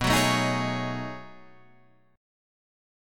B13 Chord
Listen to B13 strummed